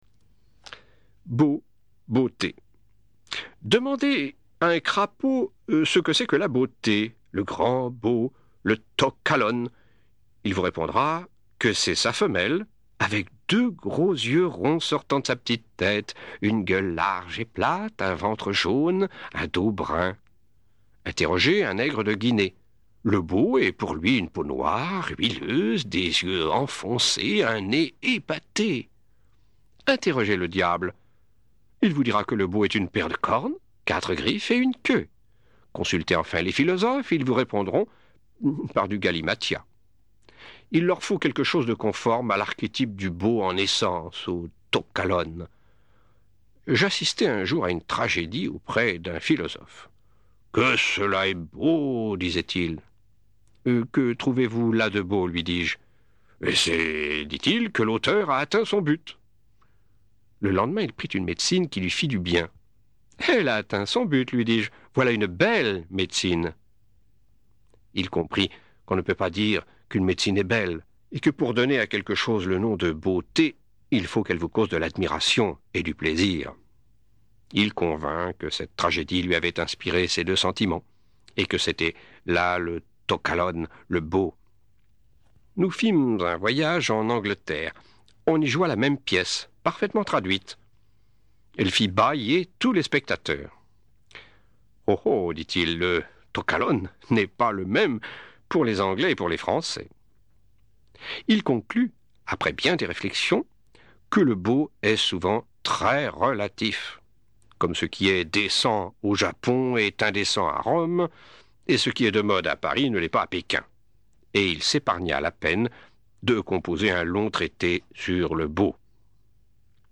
je découvre un extrait